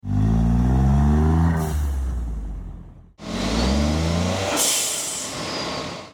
Delivers the classic “whoosh” sound whilst protecting your turbo, the SXV Mini is also built to last, backed by our lifetime warranty.